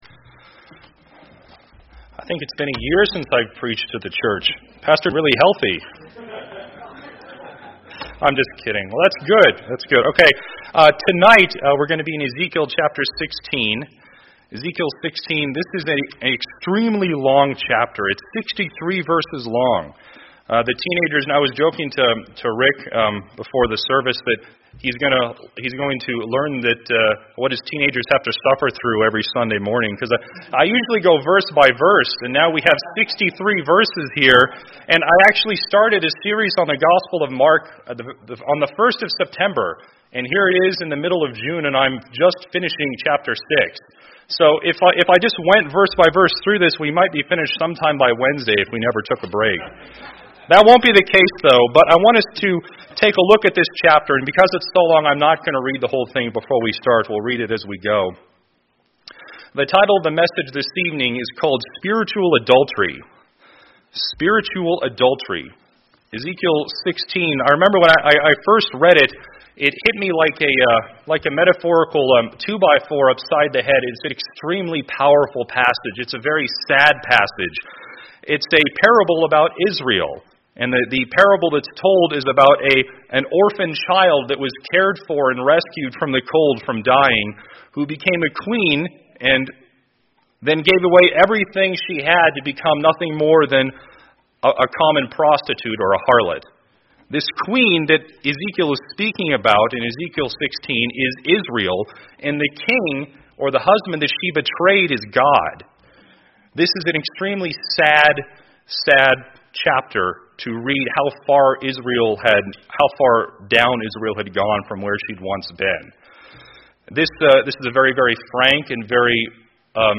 I preached this message for teen Sunday School at my church this morning.